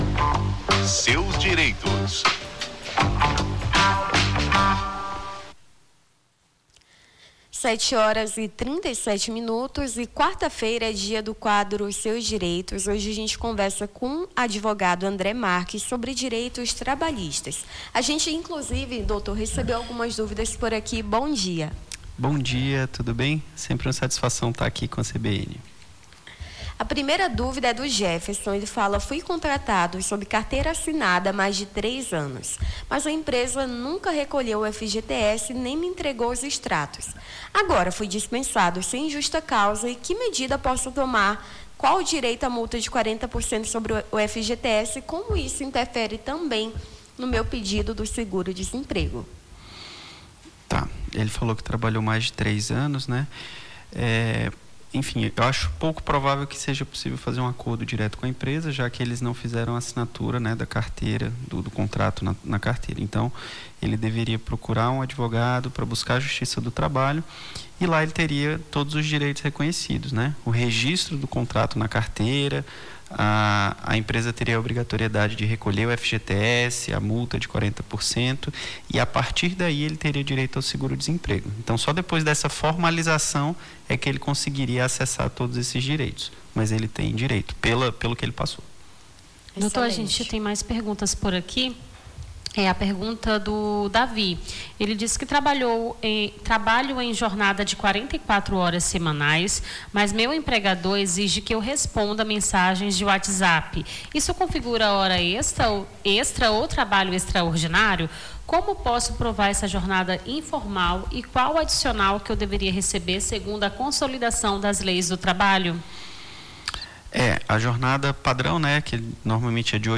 Seus Direitos: advogado tira dúvidas sobre direitos trabalhistas